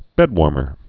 (bĕdwôrmər)